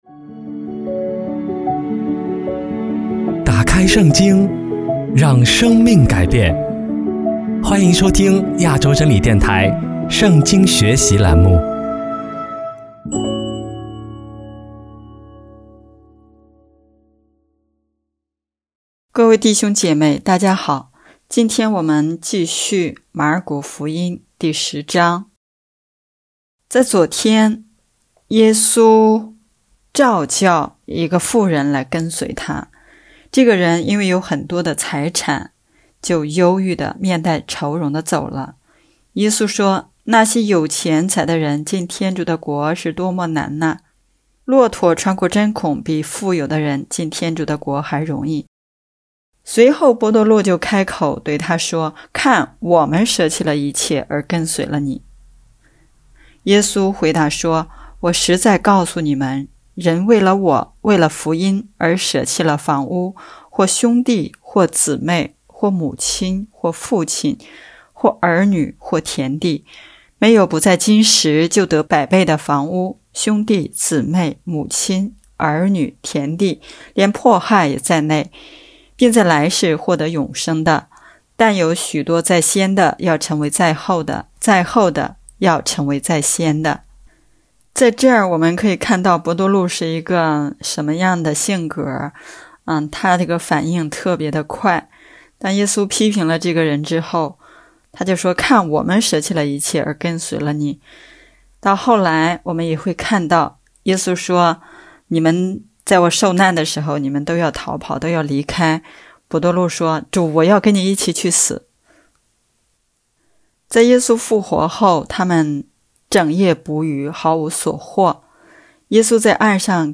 【圣经课程】|马尔谷福音第十讲